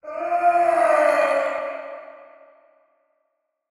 GuardScream3.ogg